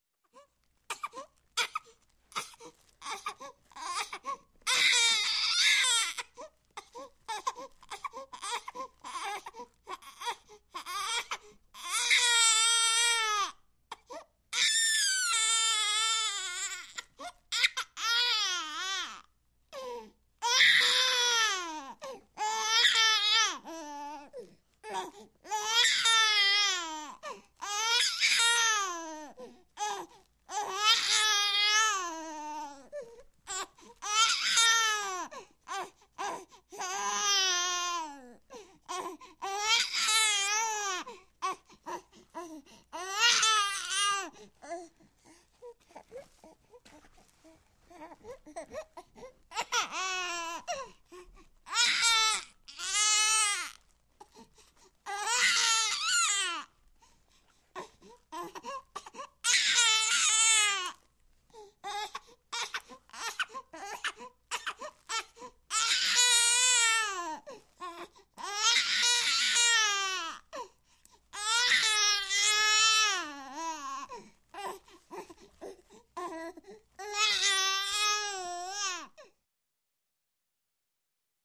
На этой странице собраны различные звуки плача младенцев: от тихого хныканья до громкого крика.
7 недель маленькой девочки